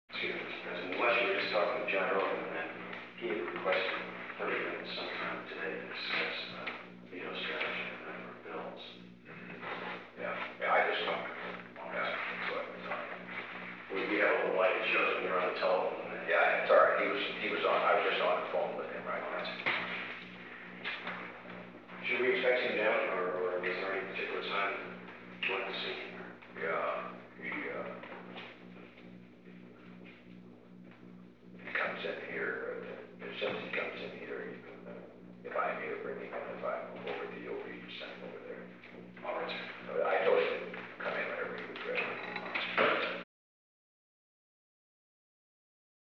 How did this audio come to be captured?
Recording Device: Oval Office The Oval Office taping system captured this recording, which is known as Conversation 747-012 of the White House Tapes.